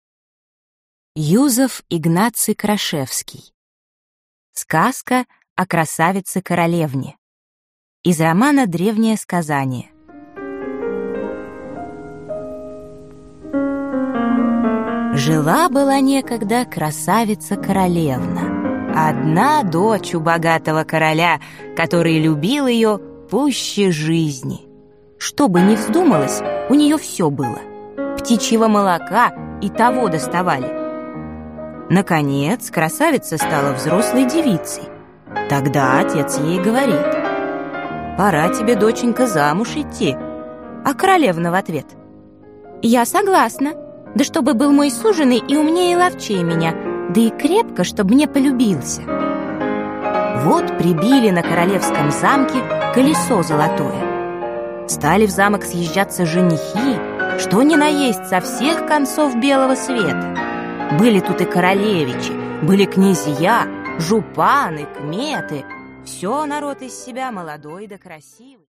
Аудиокнига Сказки польских писателей о гномах и королевнах | Библиотека аудиокниг